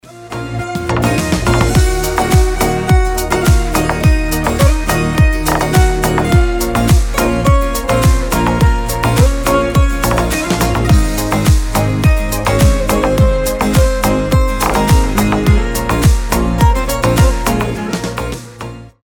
• Качество: 320, Stereo
гитара
без слов